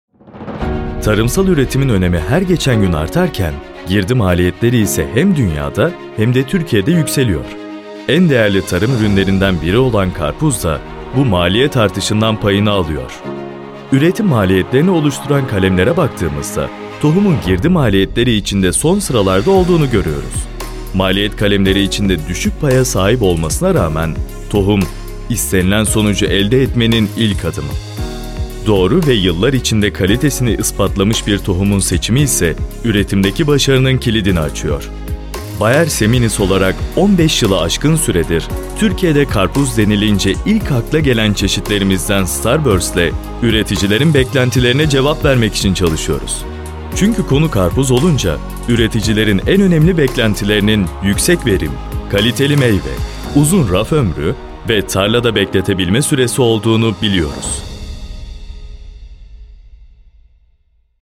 Promotional Movie Voice Over
Promotion film, advertising film is longer than the voice-over, it is more calm and narrated in terms of reading compared to the commercial film.